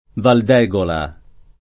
vai all'elenco alfabetico delle voci ingrandisci il carattere 100% rimpicciolisci il carattere stampa invia tramite posta elettronica codividi su Facebook Val d’Egola [ val d $g ola ] o Valdegola [ id. ] top. f. (Tosc.)